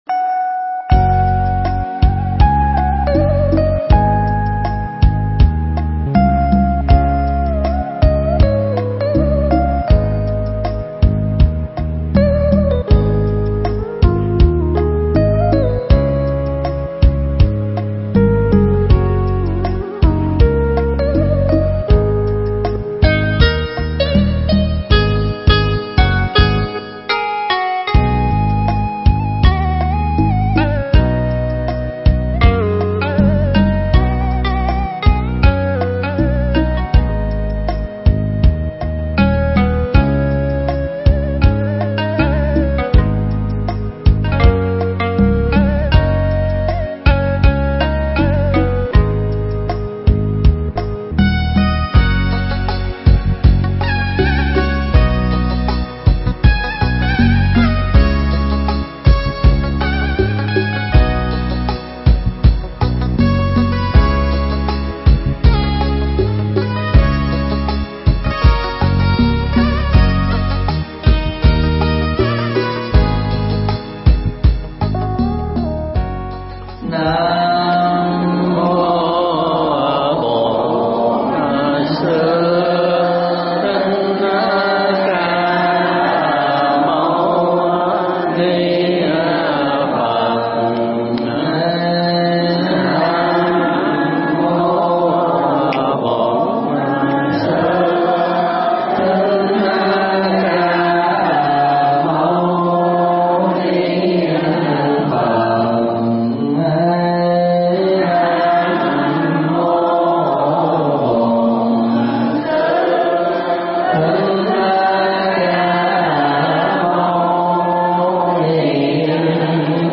Mp3 Thuyết Pháp Đừng Để Bị Lòng Tham Chi Phối
giảng tại chùa Linh Quang (Bang Pennsylvania, Hoa Kỳ)